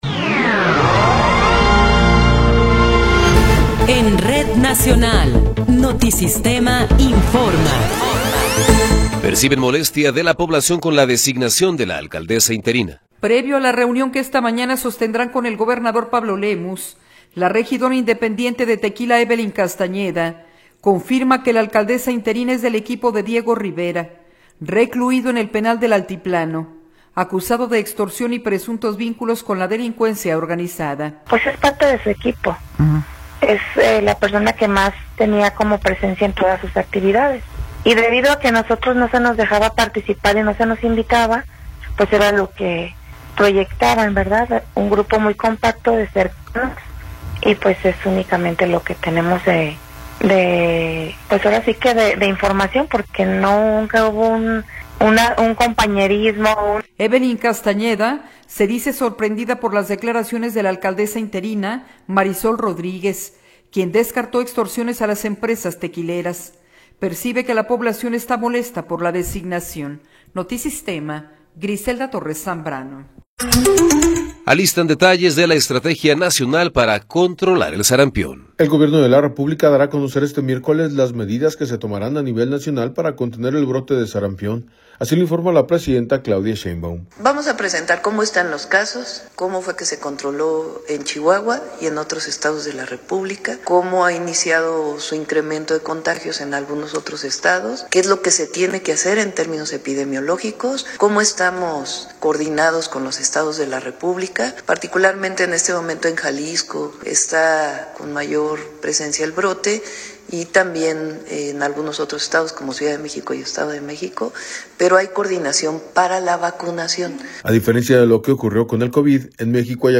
Noticiero 11 hrs. – 10 de Febrero de 2026
Resumen informativo Notisistema, la mejor y más completa información cada hora en la hora.